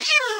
cat_hitt3.ogg